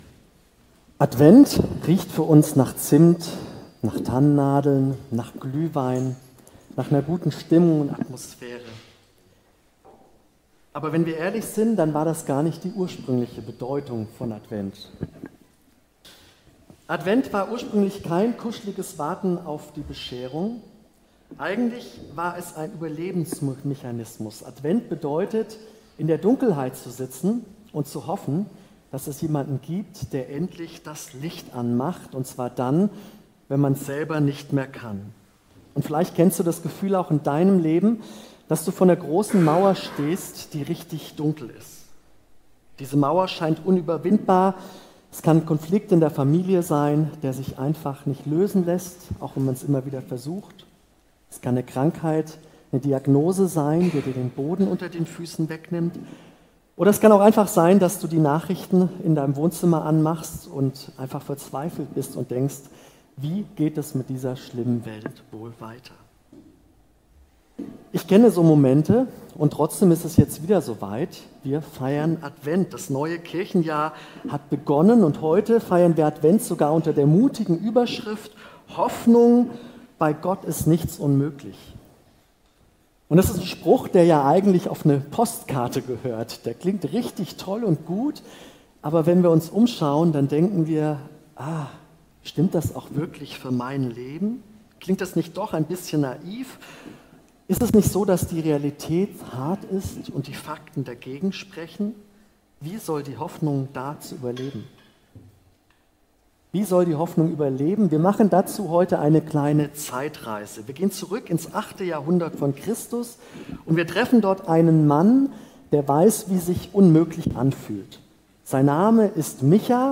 Predigt am Sonntag